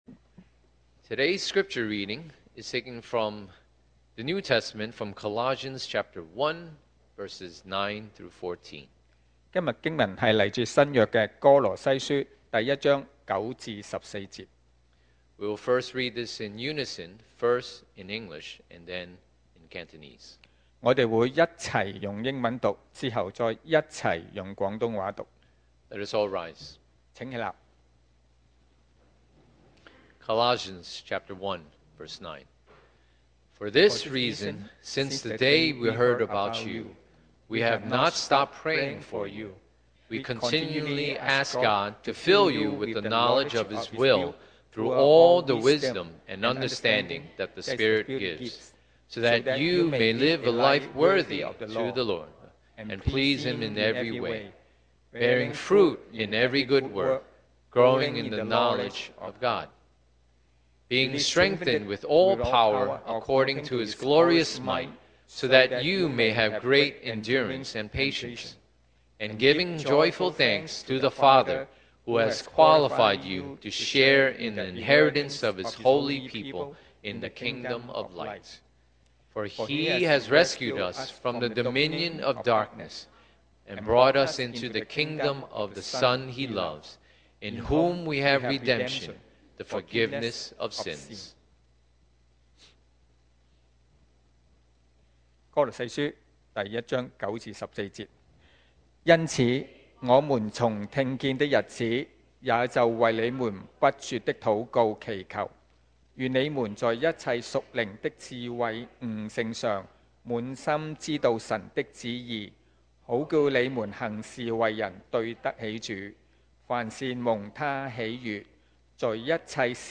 Series: 2025 sermon audios
Passage: Colossians 1:9-14 Service Type: Sunday Morning